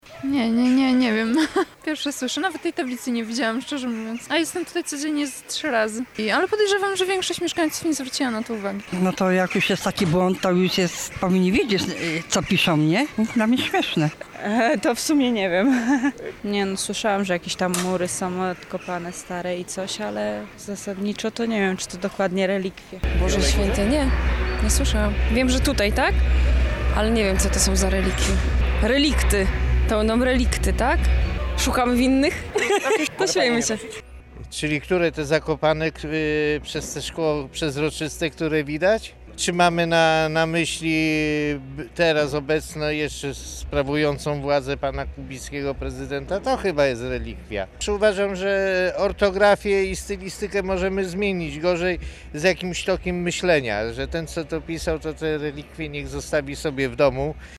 Oto, co mieszkańcy mówili na temat zielonogórskich „relikwii”: